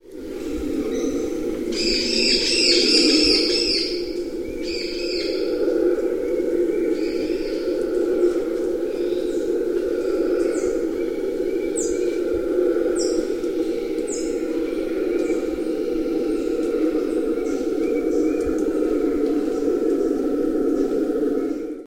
На этой странице собраны разнообразные звуки ревунов — от громких рыков до отдаленных эхо в джунглях.
Звук обезьяны-ревуна, запись из Перу